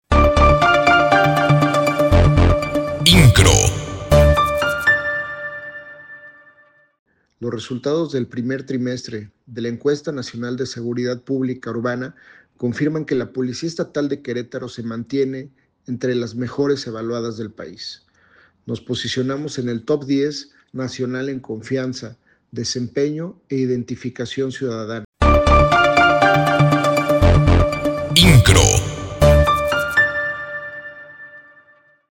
Iovan Elías Pérez Hernández, Secretario de Seguridad Ciudadana, destacó que la Policía Estatal de Querétaro se posicionó entre las corporaciones mejor evaluadas del país, de acuerdo con los resultados del primer trimestre de la Encuesta Nacional de Seguridad Pública Urbana (ENSU) que publica el Instituto Nacional de Estadística y Geografía (INEGI).